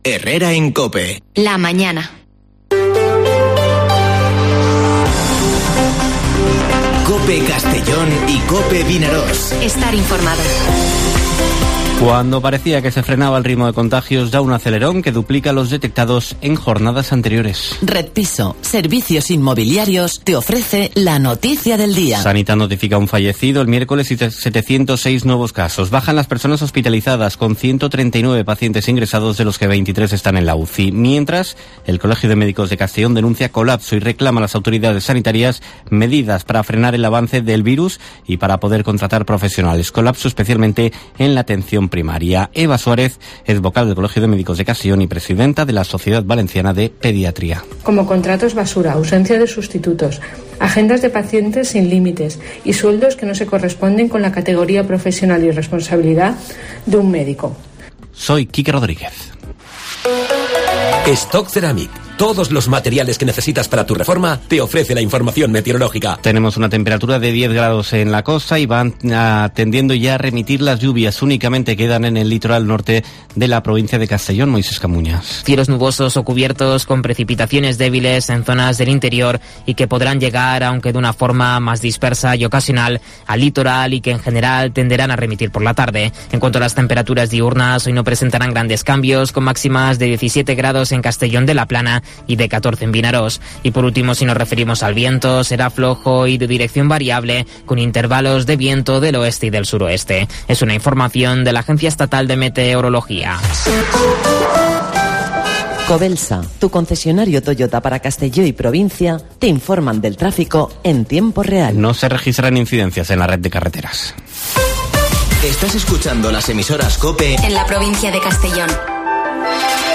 Informativo Herrera en COPE en la provincia de Castellón (24/12/2021)